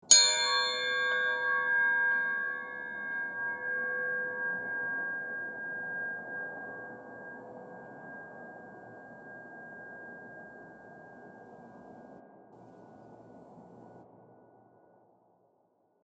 AV_Church_FX.mp3